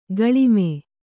♪ gaḷime